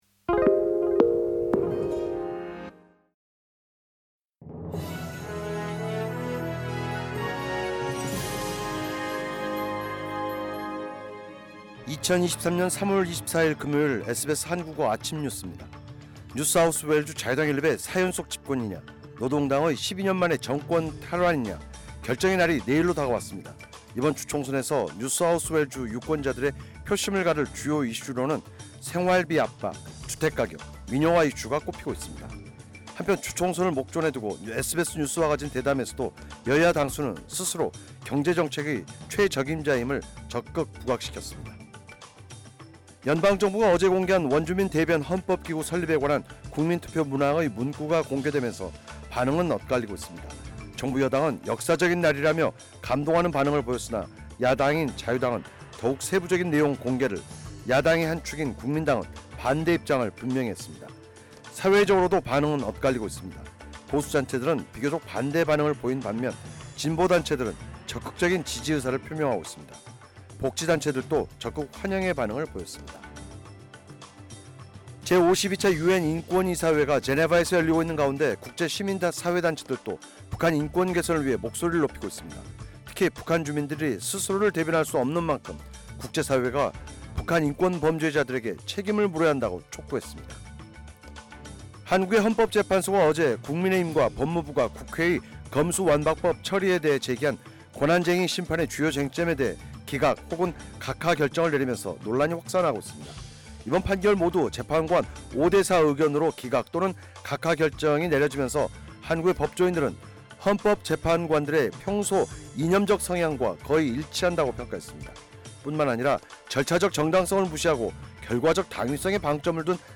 SBS 한국어 아침 뉴스: 2023년 3월 24일 금요일